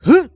XS格挡1.wav
XS格挡1.wav 0:00.00 0:00.38 XS格挡1.wav WAV · 33 KB · 單聲道 (1ch) 下载文件 本站所有音效均采用 CC0 授权 ，可免费用于商业与个人项目，无需署名。
人声采集素材